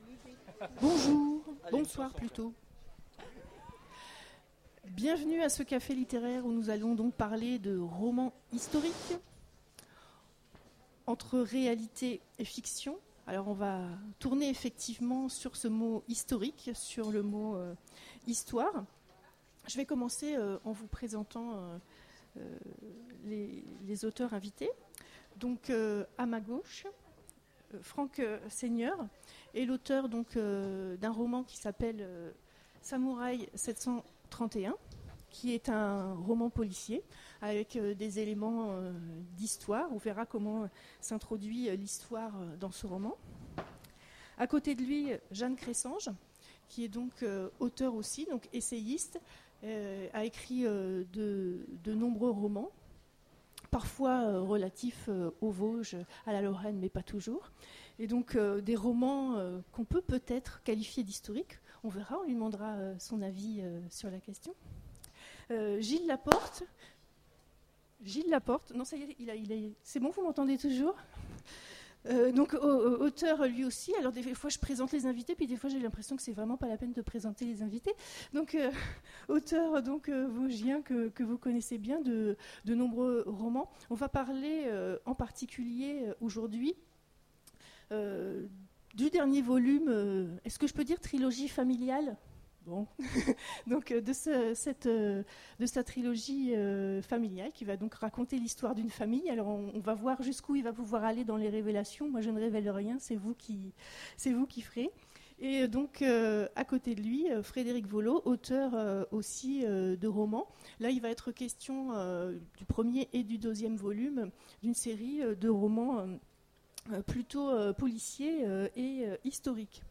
Imaginales 2015 : Conférence Le roman historique
Conférence